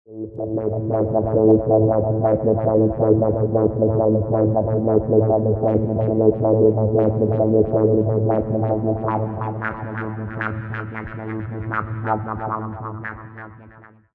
Note that the sound itself is not comming from the LFOs, but from a MIDI synthesizer. The LFOs are used to modulate the sound parameters like VCO Frequency, CutOff Frequency, Resonance, Sync., Effect parameters of the synth.
Using three LFOs: 2 (485 k)